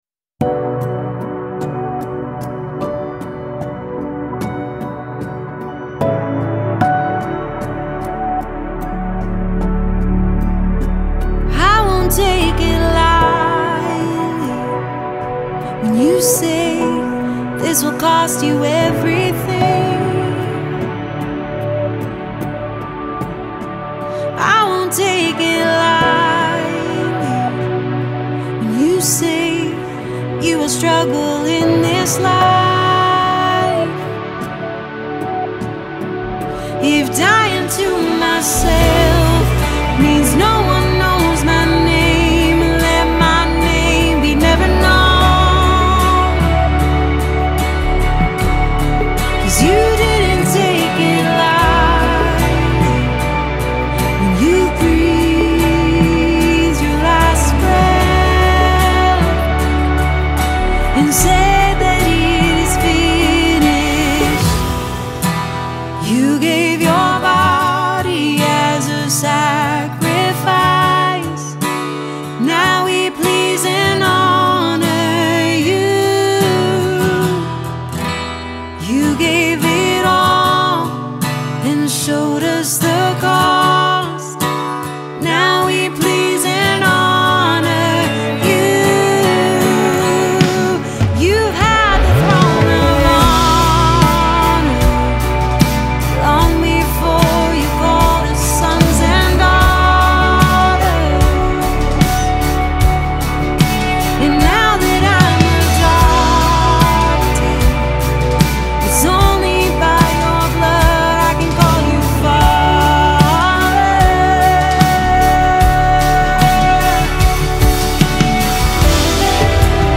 94 просмотра 77 прослушиваний 0 скачиваний BPM: 75